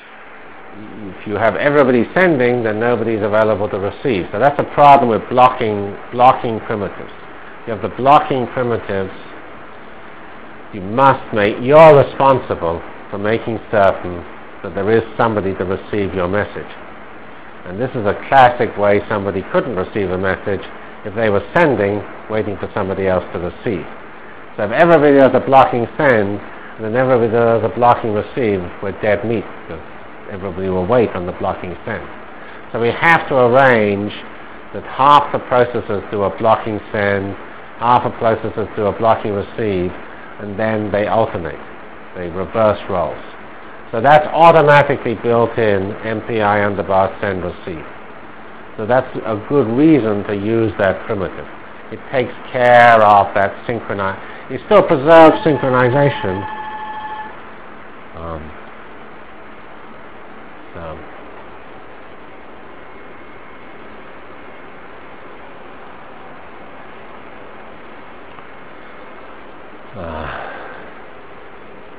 From CPS615-Completion of MPI foilset and Application to Jacobi Iteration in 2D Delivered Lectures of CPS615 Basic Simulation Track for Computational Science -- 7 November 96. by Geoffrey C. Fox